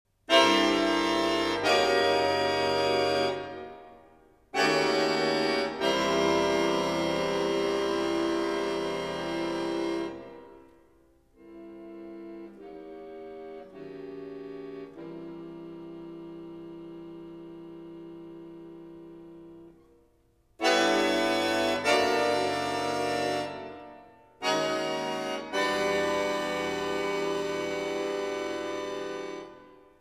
Allegro con fuoco
Andante Cantabile
2. Tranquillo